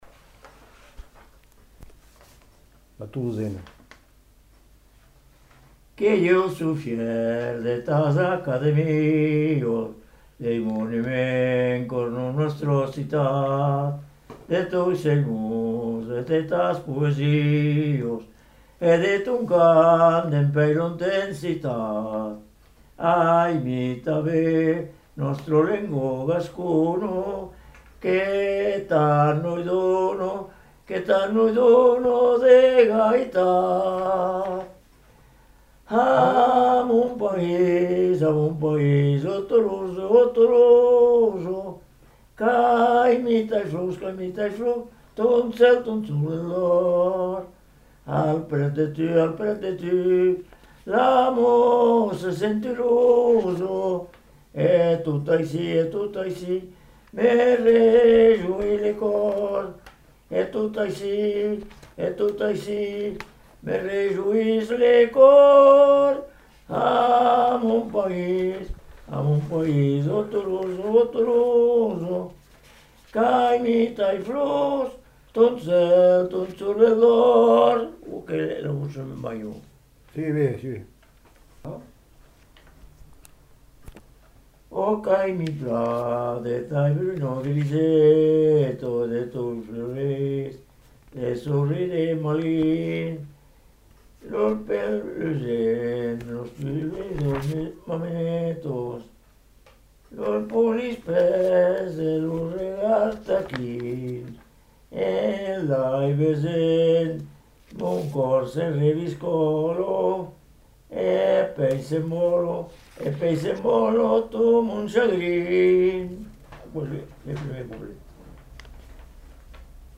Aire culturelle : Lauragais
Lieu : Caraman
Genre : chant
Effectif : 1
Type de voix : voix d'homme
Production du son : chanté
Classification : chanson identitaire